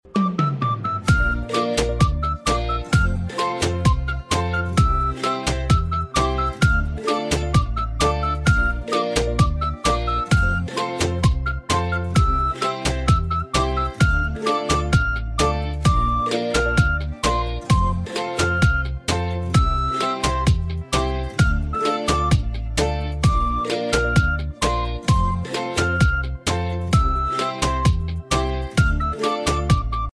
Sound Buttons: Sound Buttons View : Calma Flauta